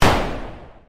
turret_fire.ogg